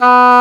WND D OBOE09.wav